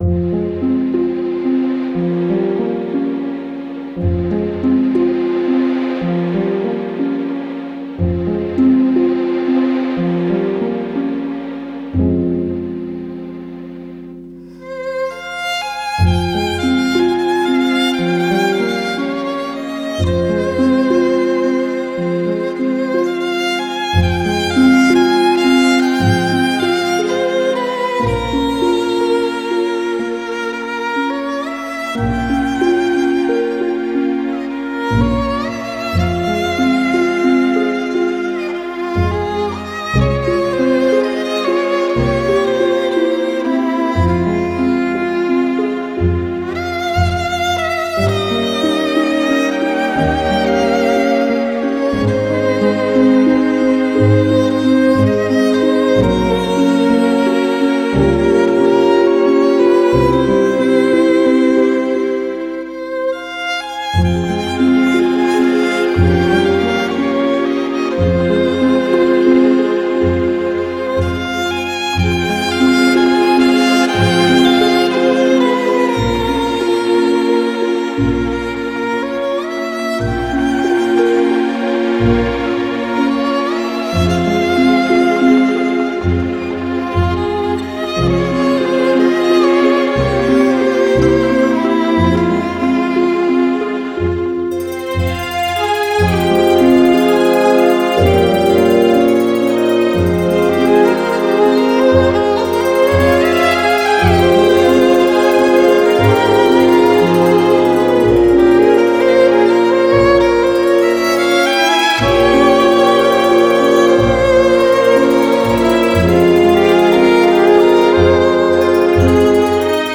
Modern Electric show tracks